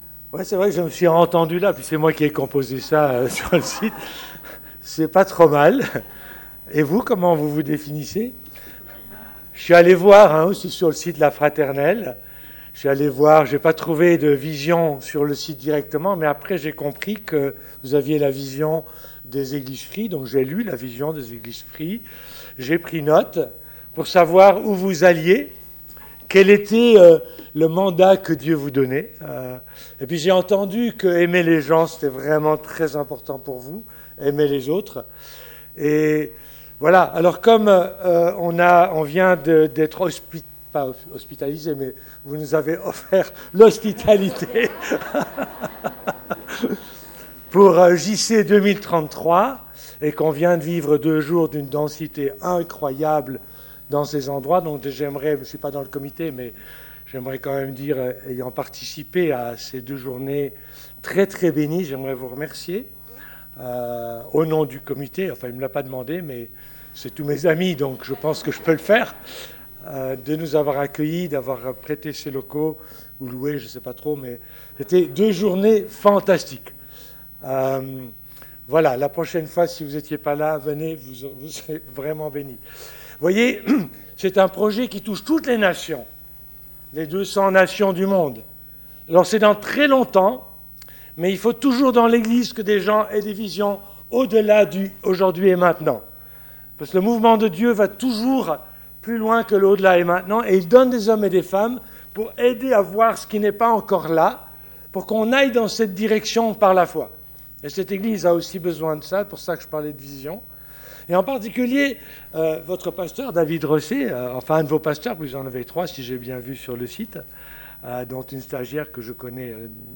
Culte du 18 novembre 2018 « La résurrection »